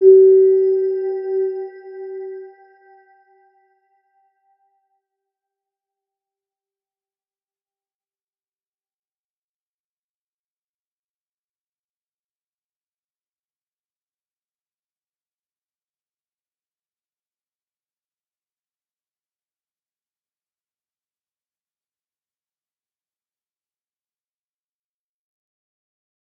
Round-Bell-G4-mf.wav